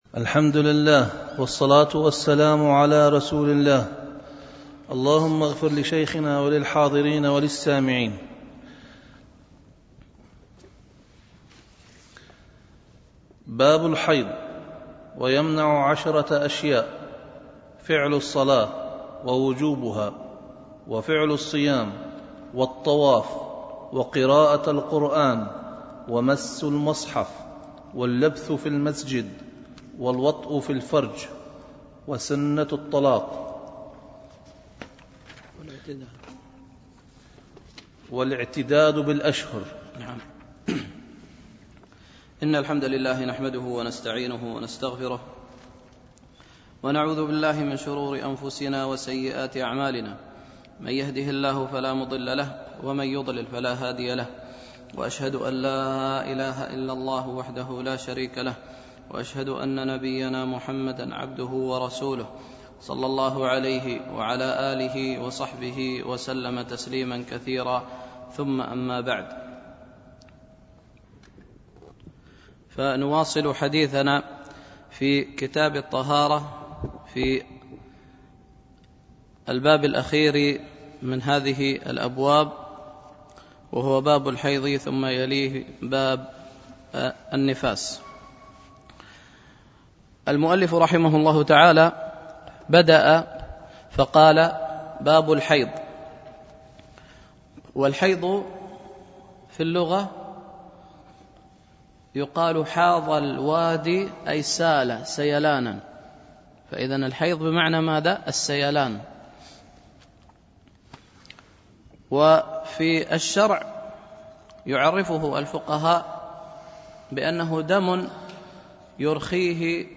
شرح عمدة الفقه ـ الدرس الثالث عشر
دروس مسجد عائشة